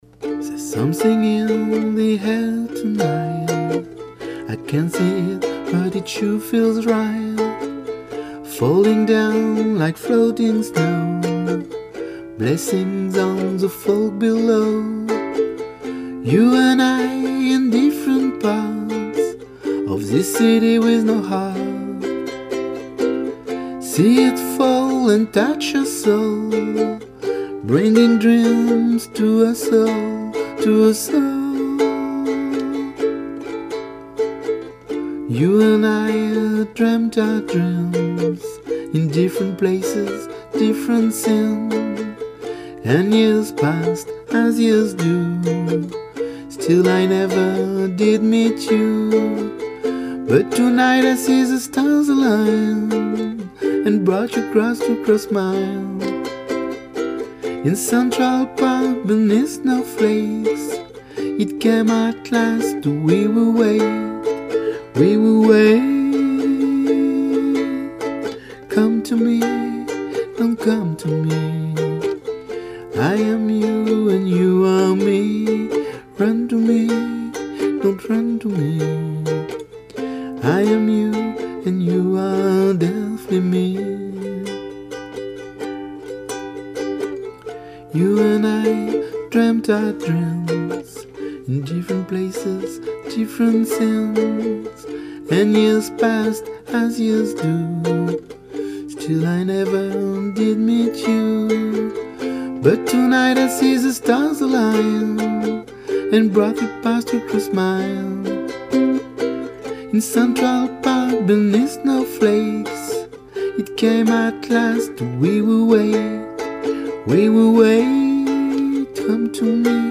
D’où ces simples maquettes proposées depuis le début de l'année, dans leur plus simple appareil, telles qu'elles ont été composé, mais pas du tout telles qu'elles sont censées être une fois terminées.
Come to me - demo ukulele/voix